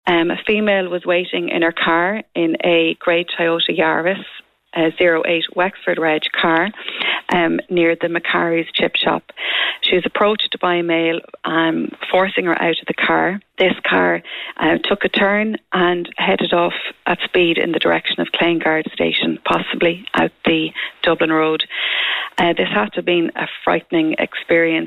Speaking on Kildare Today this morning